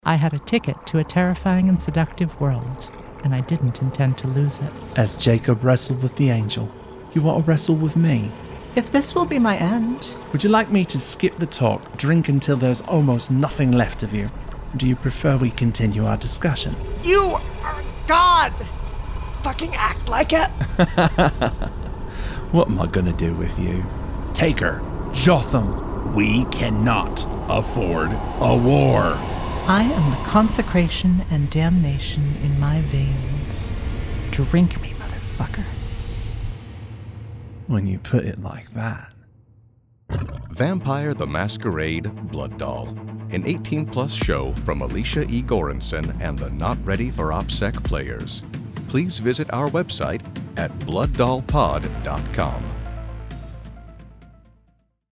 Vampire: The Masquerade: Blood Doll Audio Drama
Vampire: The Masquerade: Blood Doll is a sexy, blasphemous, and suspenseful prestige audio drama written and produced by Alicia E. Goranson and performed by the Not Ready for Opsec Players.